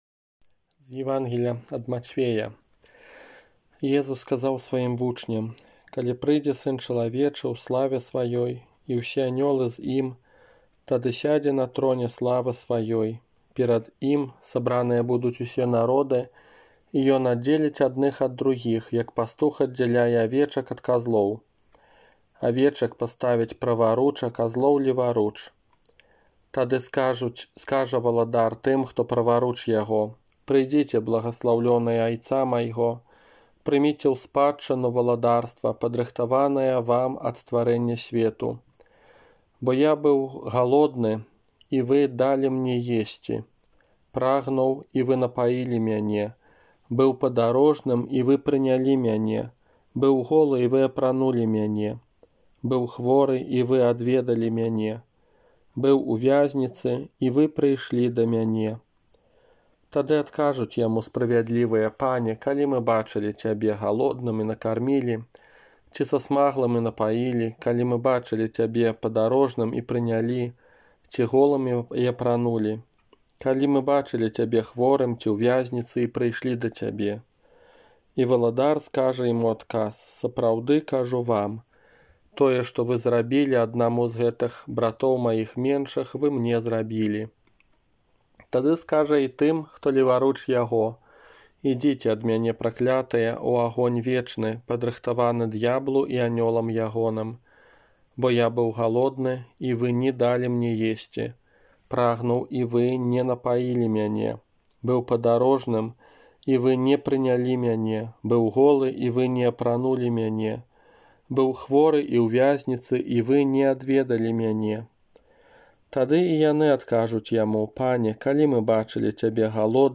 ОРША - ПАРАФІЯ СВЯТОГА ЯЗЭПА
Казанне на ўрачыстасць Пана нашага Езуса Хрыста, Валадара Сусвету 22 лістапада 2020 года